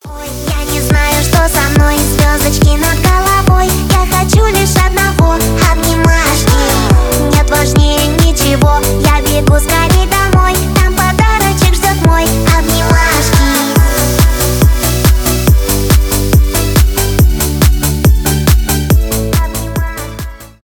детская музыка
поп
детский голос